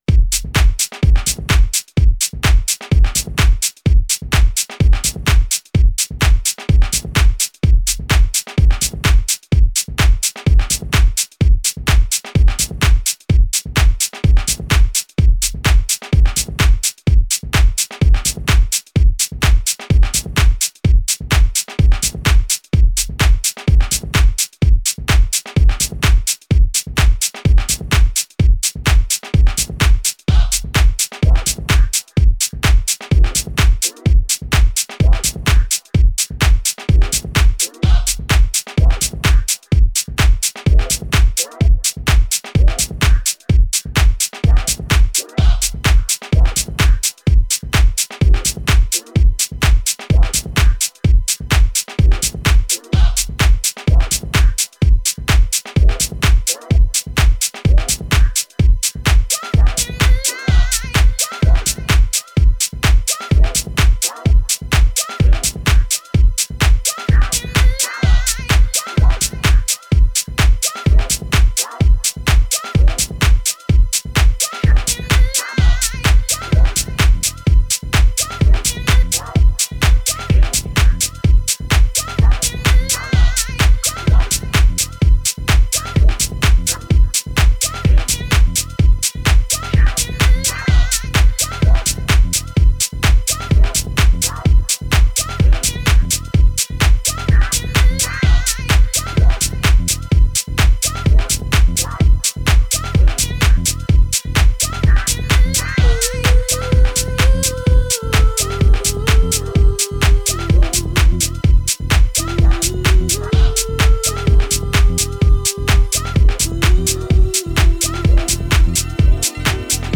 Настроение у меня сегодня ближе к стилю garage, так что результат соответствующий Качать здесь: